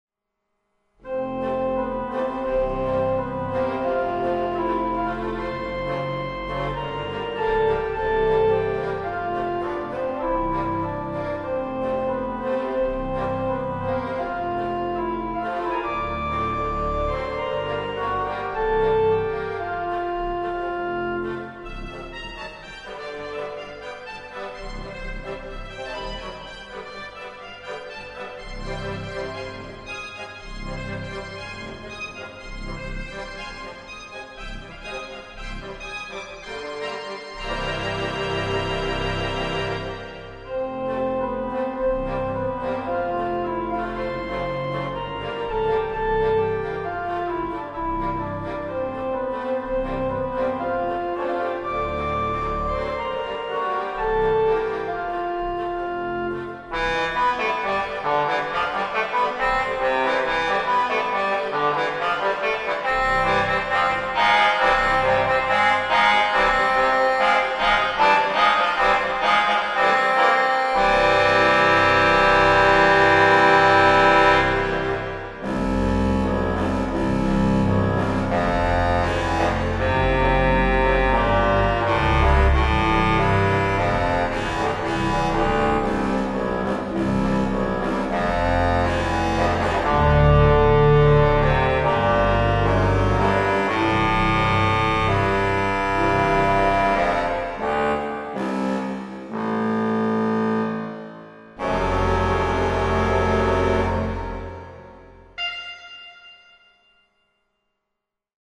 Here you can listen to screeching renditions of songs you will barely recognize!
Melody in F by Anton Rubenstein - my playing a humorous version  of this classic.
This is played on the Masterworks 3-31 theatre organ sample set.